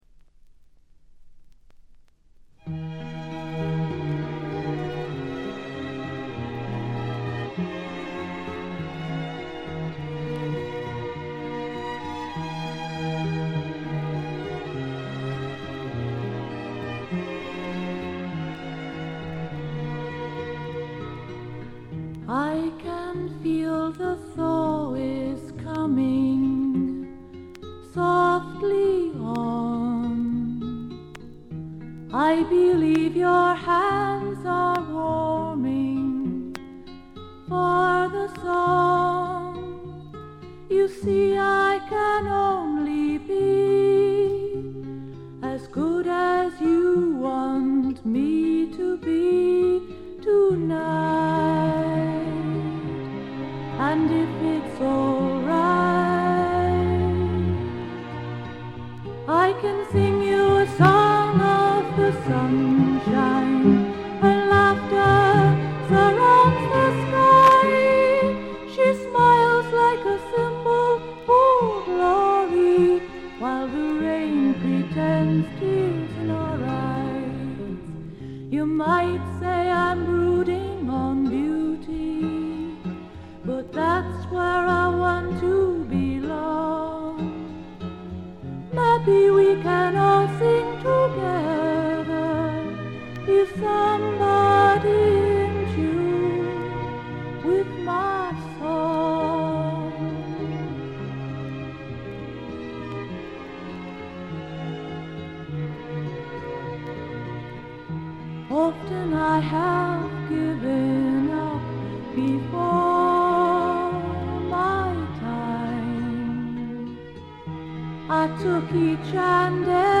曲はすべて自作で、いかにも英国の女性シンガー・ソングライターらしいポップながら陰影のある曲が並びます。
試聴曲は現品からの取り込み音源です。
vocal, acoustic guitar